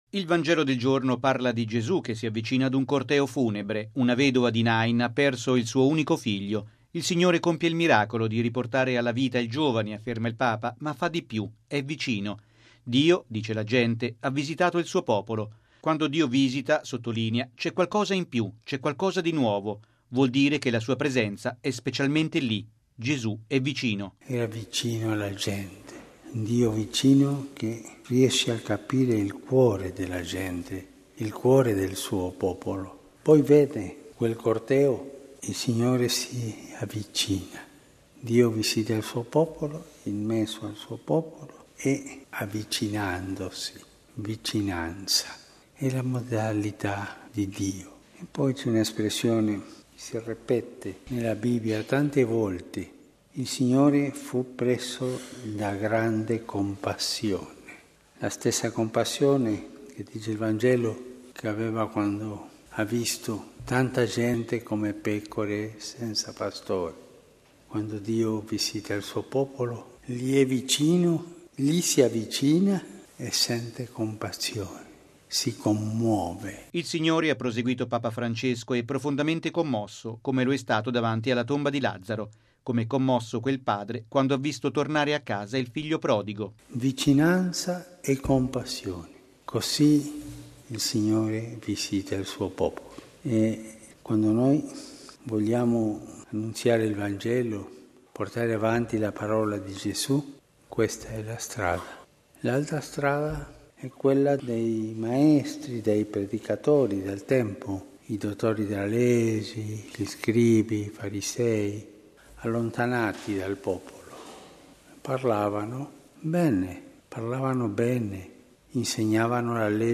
Si possono fare belle prediche, ma se non si è vicini alle persone, se non si soffre con la gente e non si dà speranza, quelle prediche non servono, sono vanità: è quanto ha detto il Papa nell’omelia mattutina a Santa Marta, nel giorno in cui la Chiesa ricorda i Santi Cornelio, Papa, e Cipriano, vescovo, martiri.